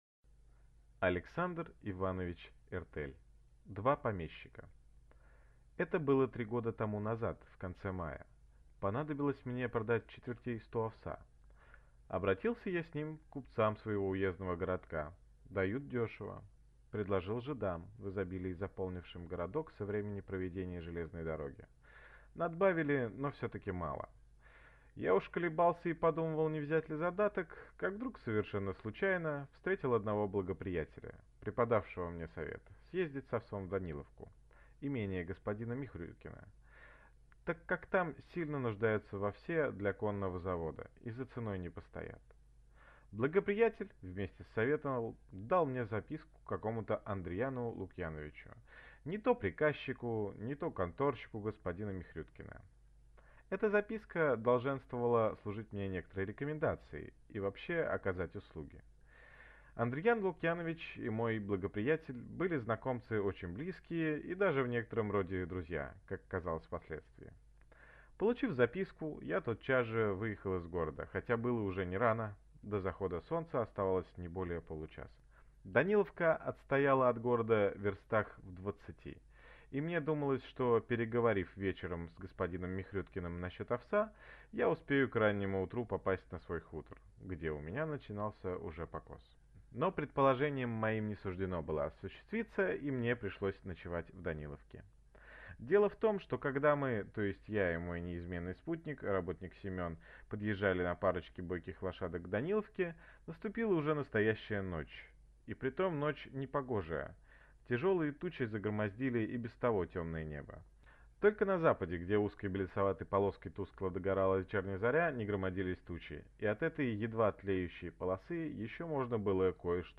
Aудиокнига Два помещика